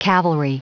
Prononciation du mot cavalry en anglais (fichier audio)
Prononciation du mot : cavalry